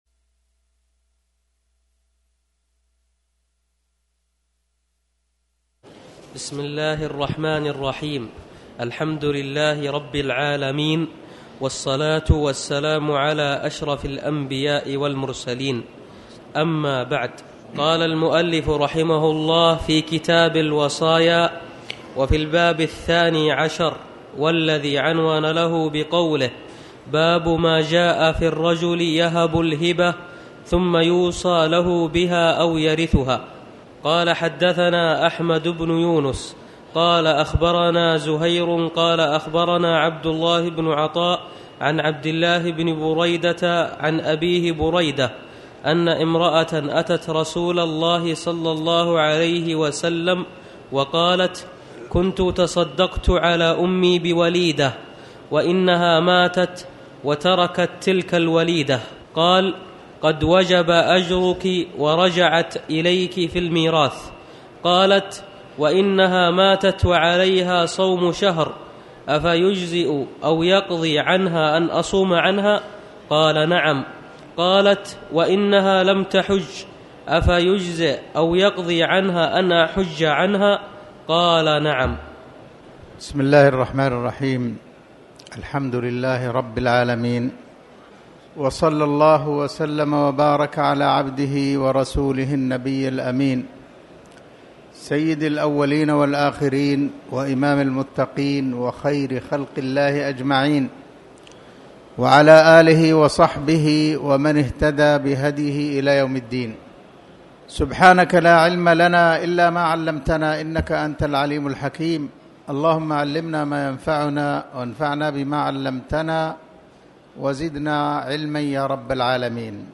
تاريخ النشر ١٨ رجب ١٤٣٩ هـ المكان: المسجد الحرام الشيخ